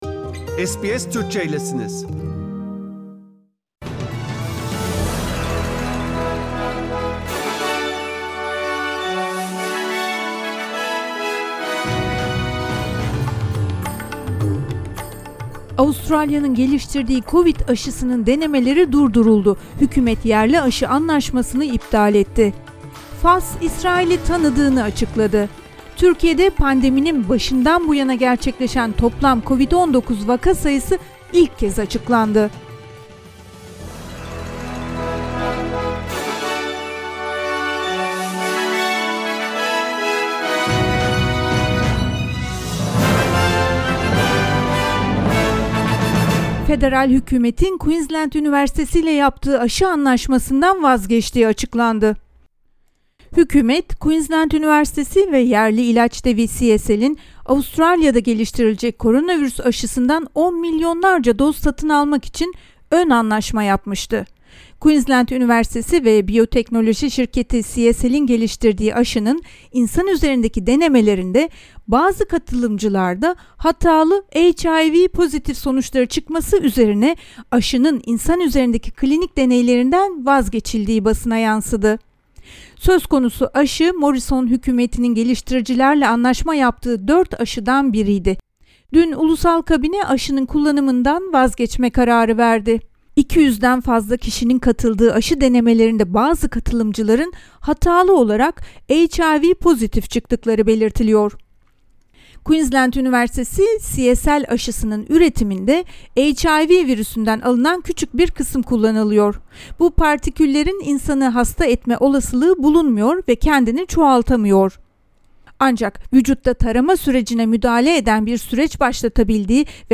SBS Türkçe'den Avustralya, Türkiye ve dünya haberleri.